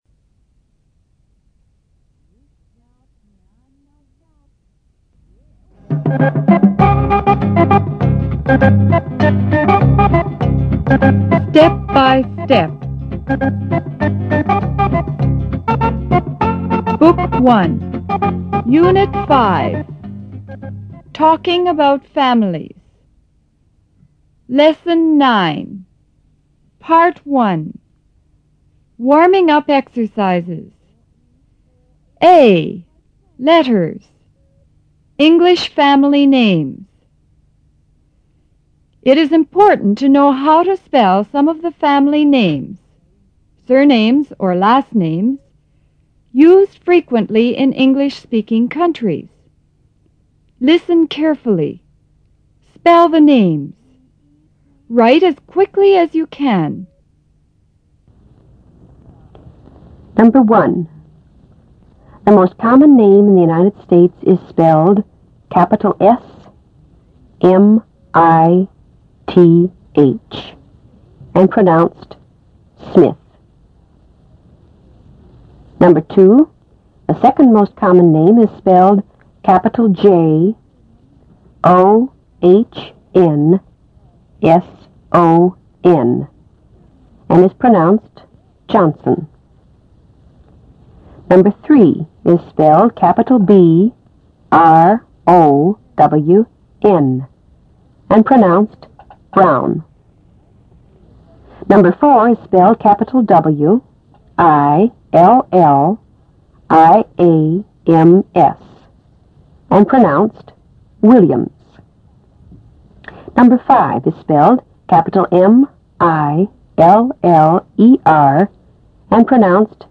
A． Letters: English Family Names
B．Sentences For Oral Repetition
Directions: You are going to hear some sentences chosen from the comprehension material in this lesson.